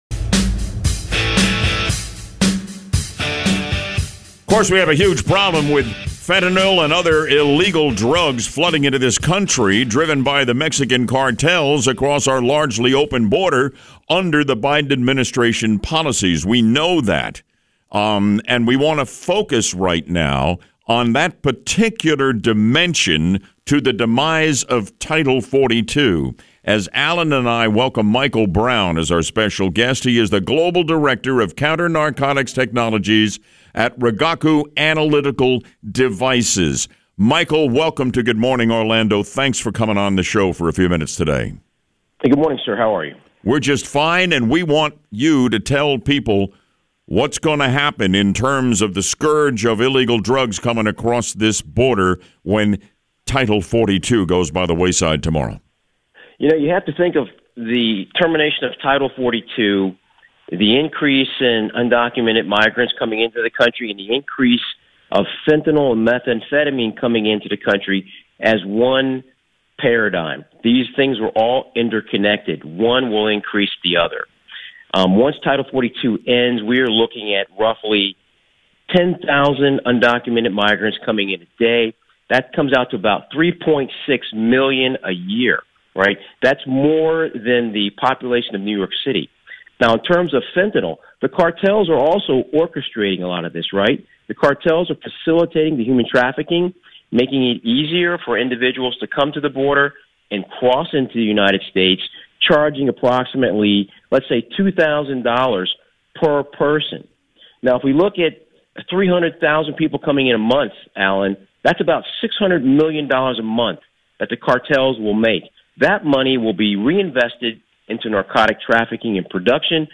Good Morning Orlando Radio Interview